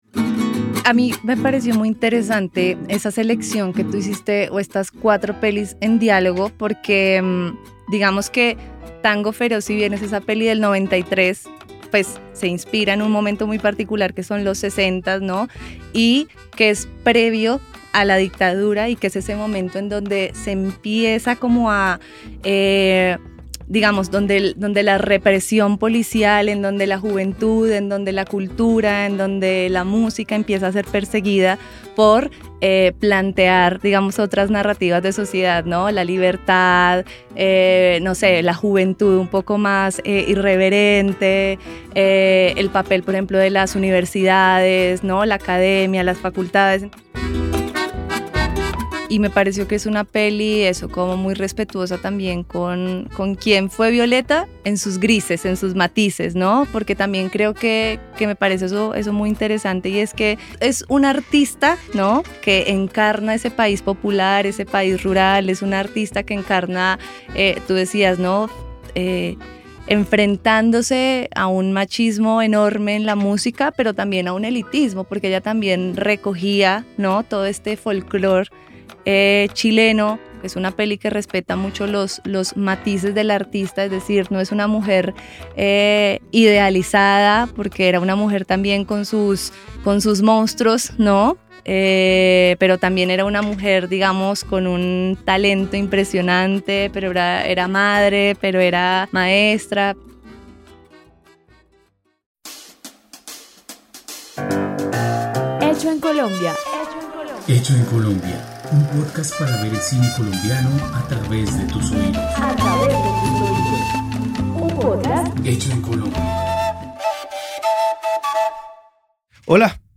Una conversación